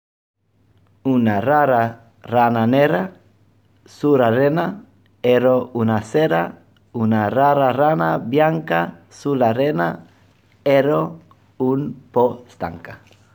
But today I propose to you a few Italian tongue-twisters read by some foreigners who live in Oslo, because I would like you to listen to their different accents and underline the beauty of each.
He also doesn´t read the word “erró” with the accent on the “ó“,  and he has a light English accent.